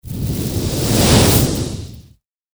魔法 | 無料 BGM・効果音のフリー音源素材 | Springin’ Sound Stock
炎の風1.mp3